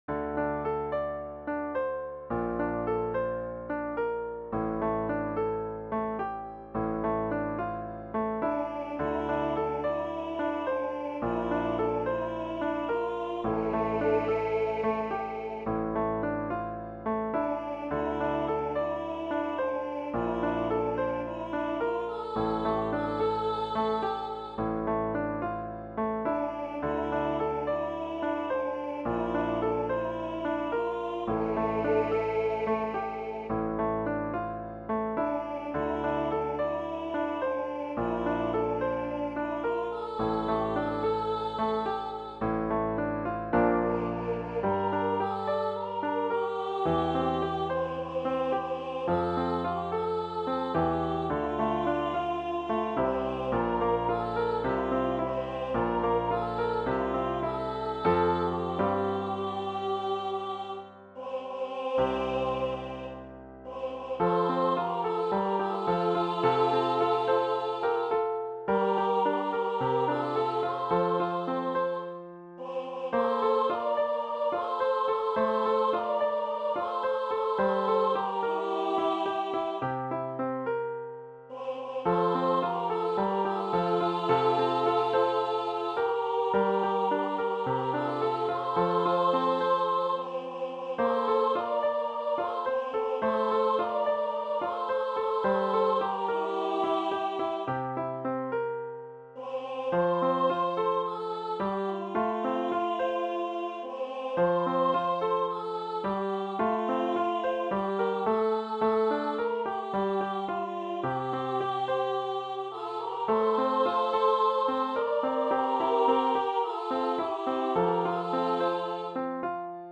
SA , Duet
Medium Voice/Low Voice